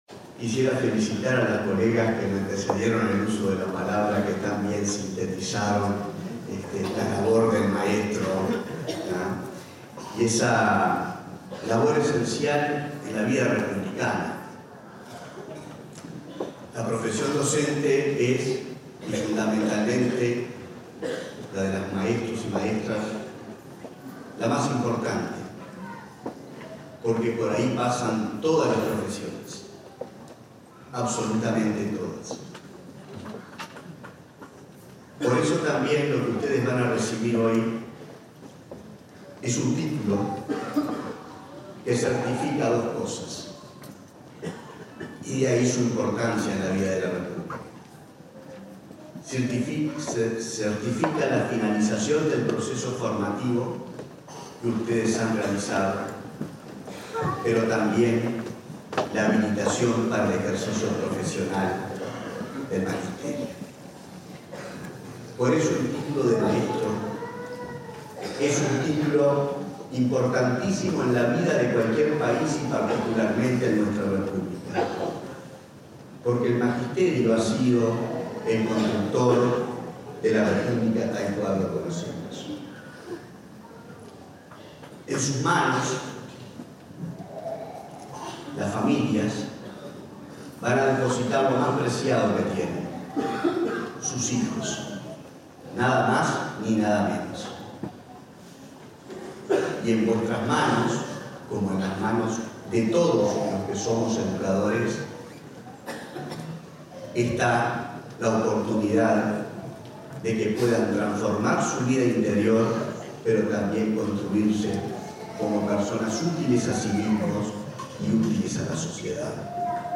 Palabras de autoridades de la ANEP
Palabras de autoridades de la ANEP 18/10/2023 Compartir Facebook X Copiar enlace WhatsApp LinkedIn El presidente del Consejo de Formación en Educación, Víctor Pizzichillo; el consejero Juan Gabito Zóboli y el director nacional de Educación, Gonzalo Baroni, participaron en el acto de colación de grado de maestros de la generación 2022-2023, realizado este miércoles 18 en el auditorio Adela Reta de Montevideo.